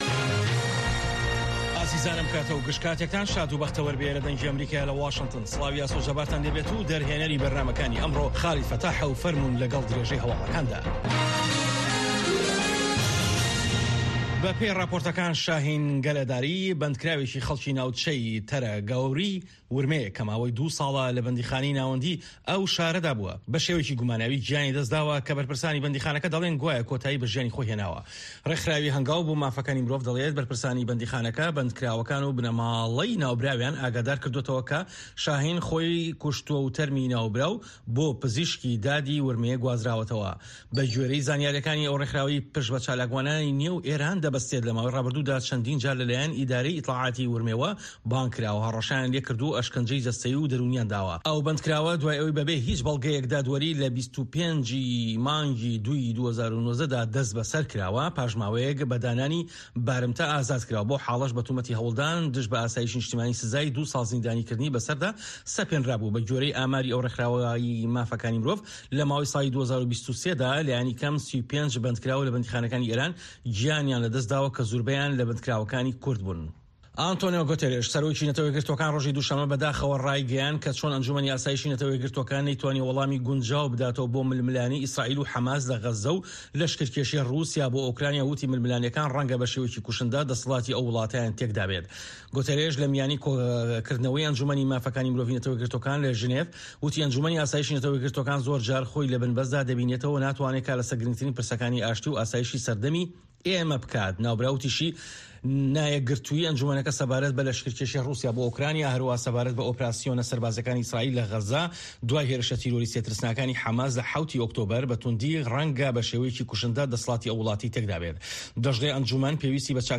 Nûçeyên 1’ê paşnîvro